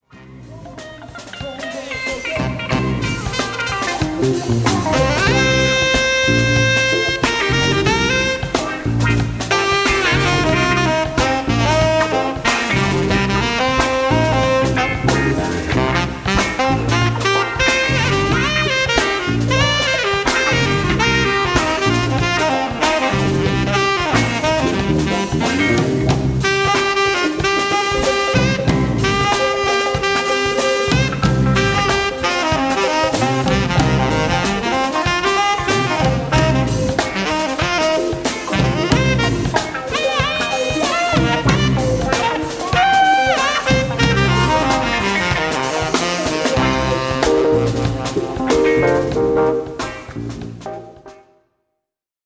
Funk:
tenor sax